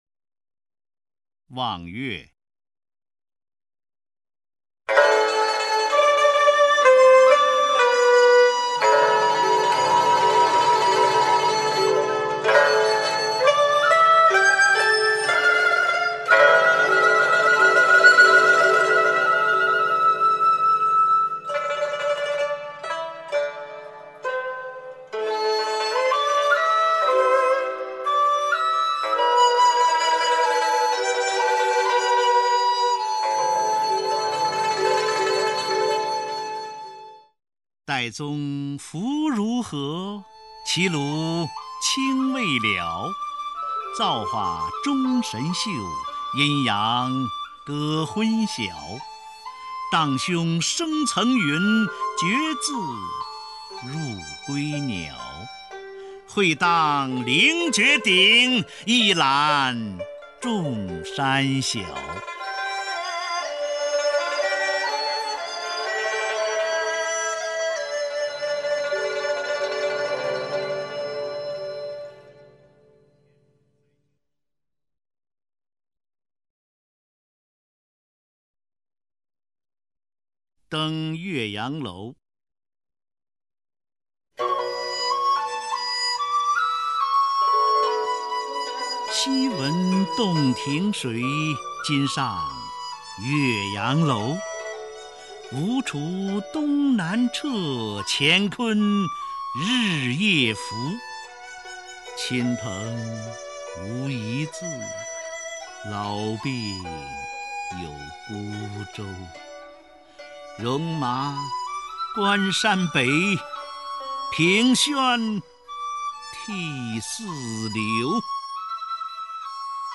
琵琶
二胡
竹笛/埙/箫
古琴